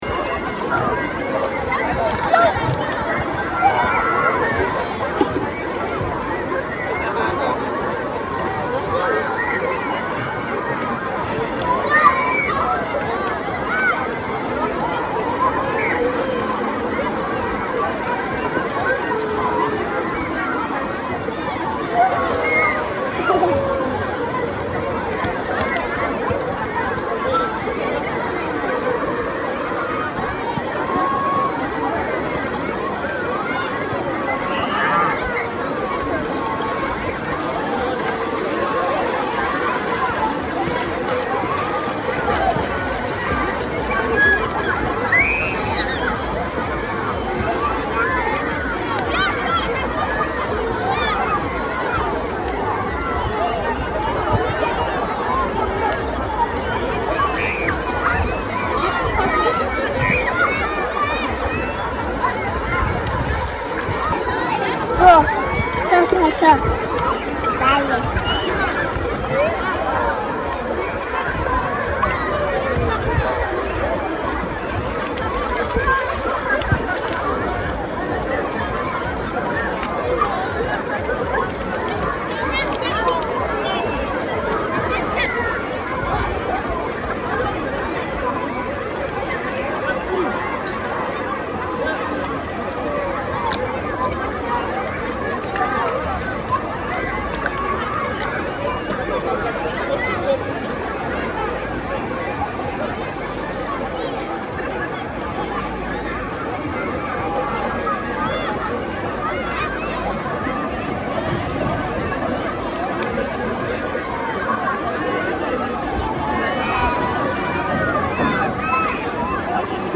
Звуки пляжа
На этой странице собраны натуральные звуки пляжа: шум волн, легкий бриз, детский смех на песке и другие уютные аудиофрагменты.